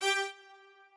strings8_16.ogg